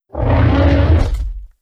Spores_Attack.wav